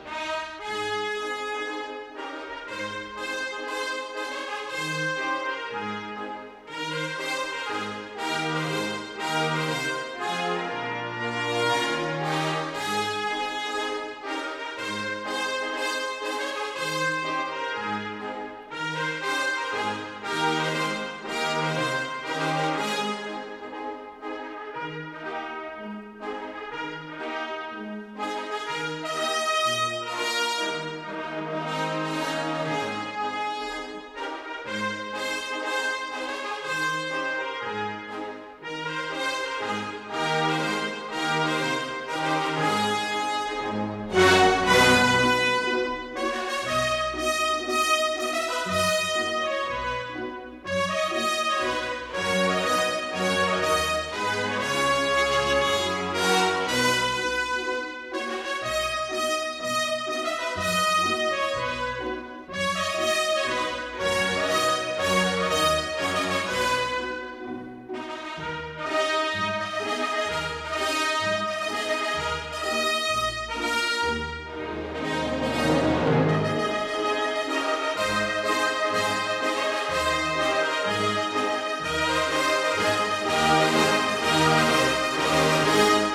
语言：意大利语